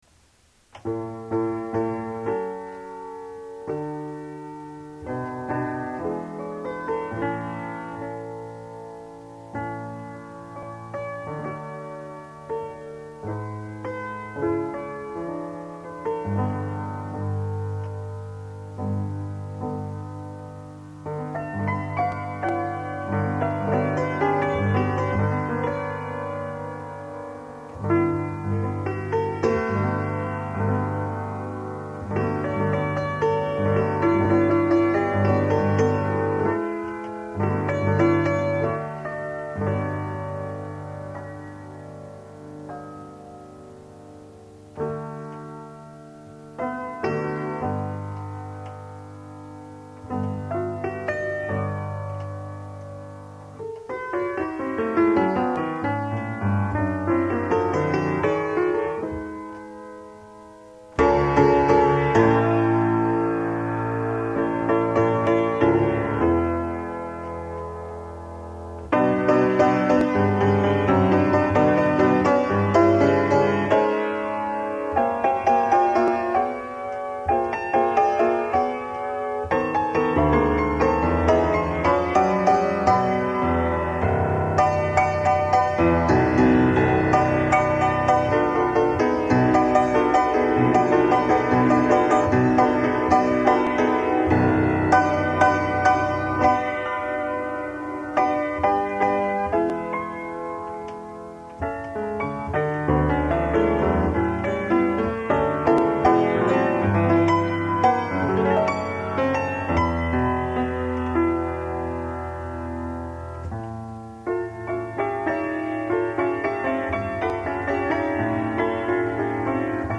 Charles Ives: "The Alcotts" (Piano Sonata No. 2, "Concord Sonata", 3rd movement) more about Charles Ives...